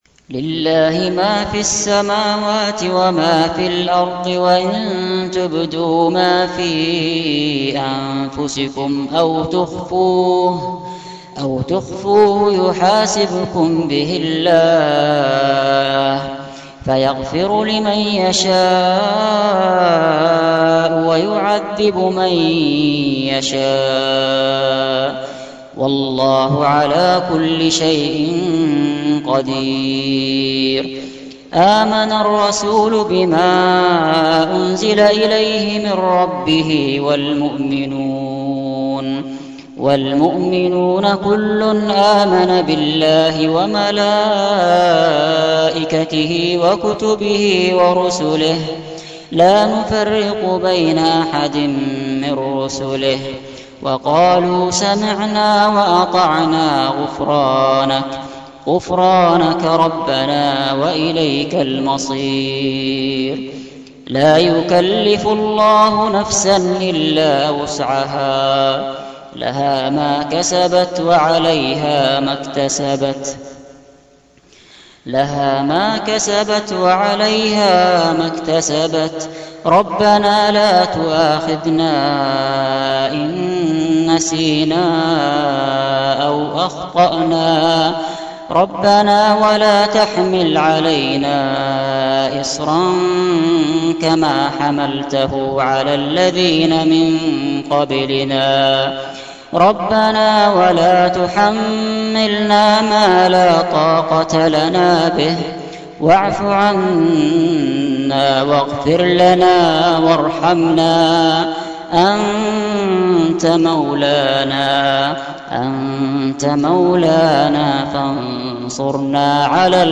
تلاوات